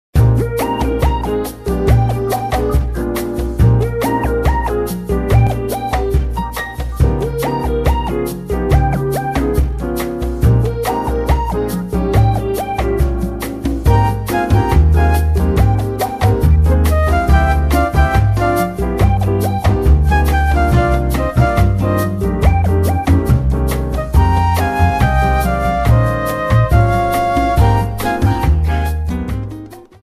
The menu theme